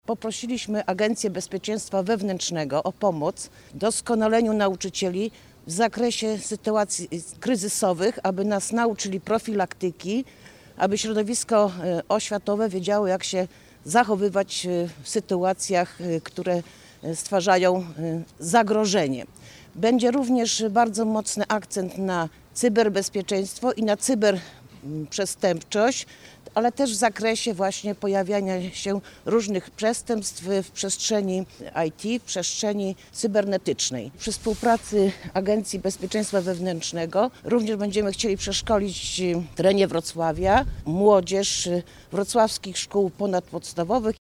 – Funkcjonariusze Agencji Bezpieczeństwa Wewnętrznego przeszkolą nauczycieli w zakresie zachowania się w sytuacjach kryzysowych – mówi Ewa Wolak, radna miejska i konsultant Wrocławskiego Ośrodka Doskonalenia Nauczycieli.